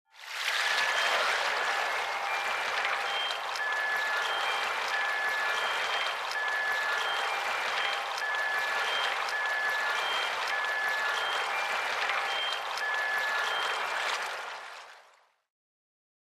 Flowing Water With Mystical Wind Chimes